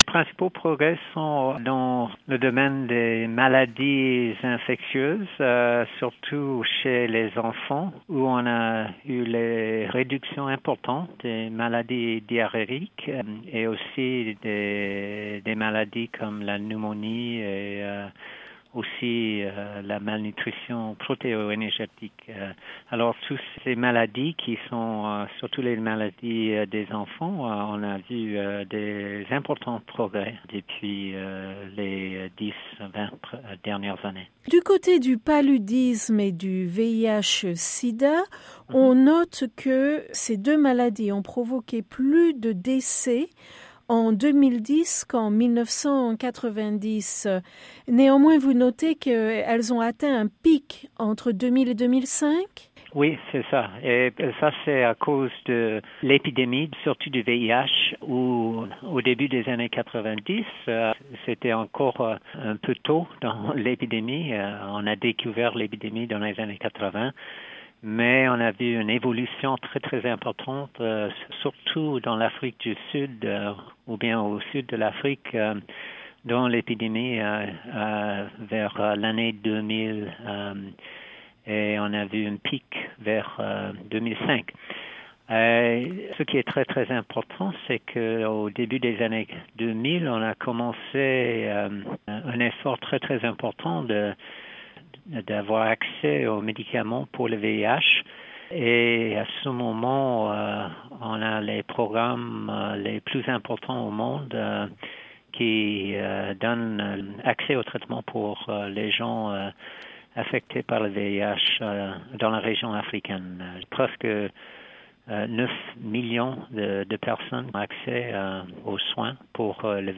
dans une interview avec la Voix de l’Amérique